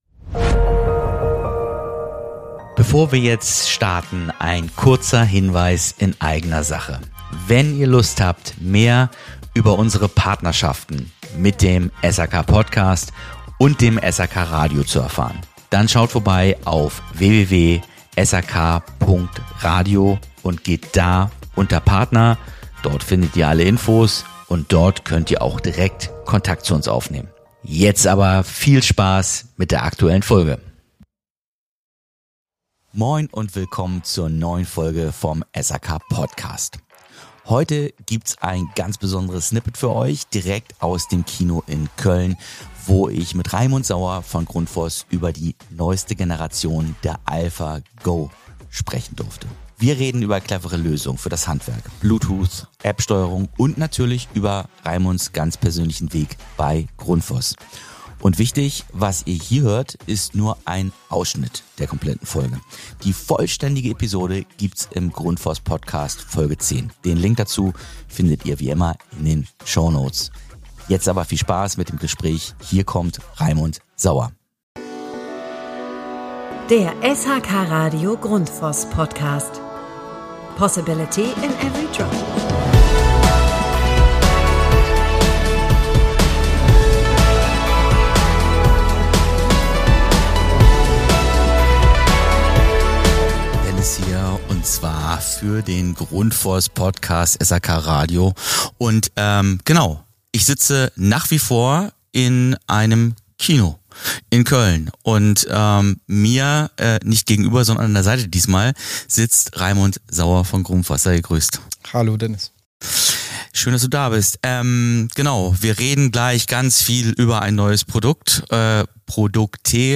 Im Fokus steht natürlich die neue ALPHA GO – eine hocheffiziente Austauschpumpe mit App-Anbindung, intuitiver Inbetriebnahme und innovativer Steckerlösung. Wichtig: Das ist nur ein Preview!